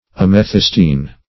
Amethystine \Am`e*thys"tine\, a. [L. amethystinus, Gr.